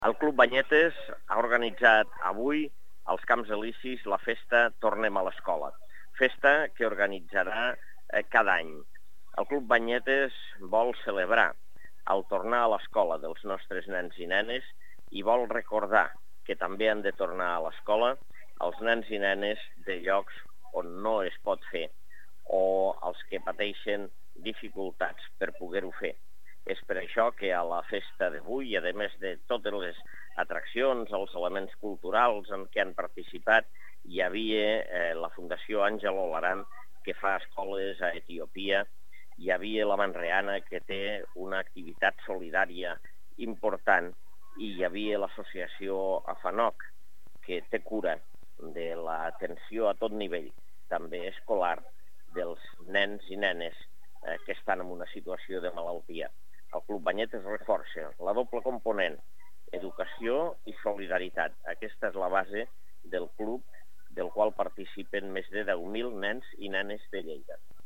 tall-de-veu-de-lalcalde-angel-ros-sobre-la-festa-de-la-tornada-a-la-lescola-del-club-banyetes